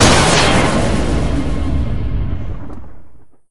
deadyey_shot_01.ogg